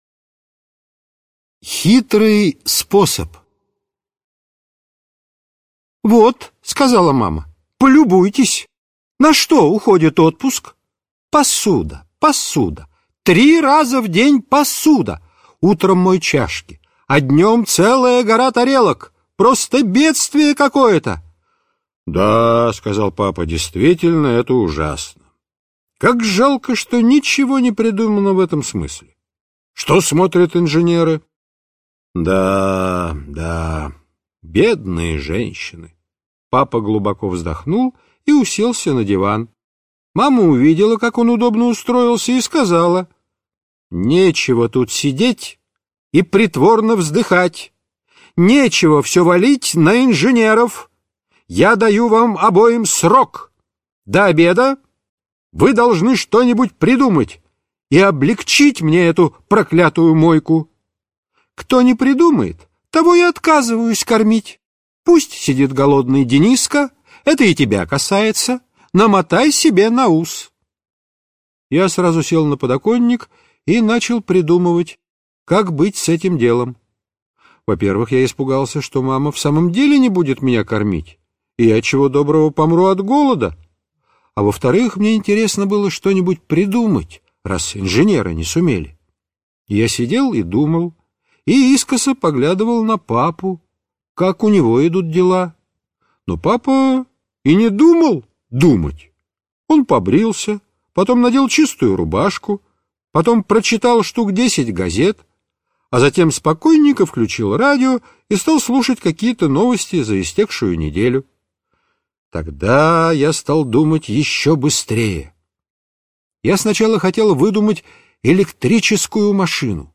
Хитрый способ - аудио рассказ Драгунского В.Ю. Мама Дениски часто возмущалась, что много времени уходит на мытье посуды...